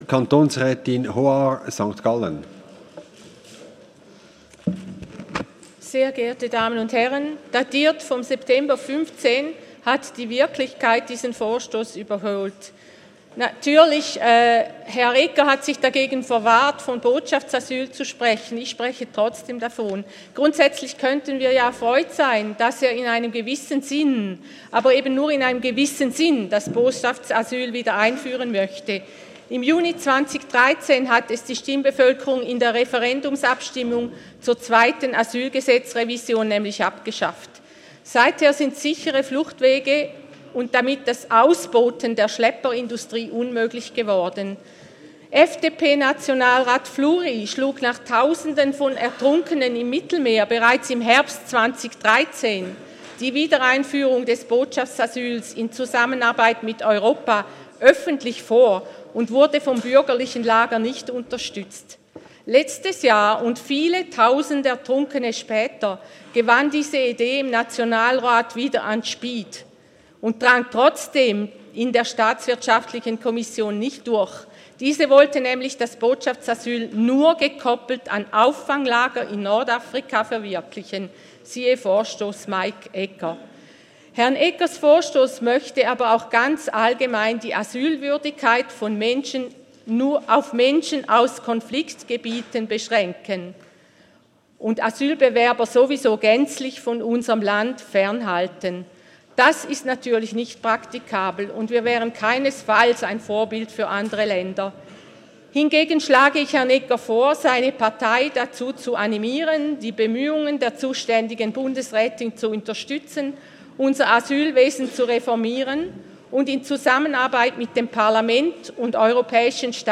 1.3.2016Wortmeldung
Session des Kantonsrates vom 29. Februar bis 2. März 2016, ausserordentliche Session vom 3. März 2016